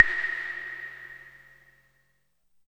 81 CLAVE  -R.wav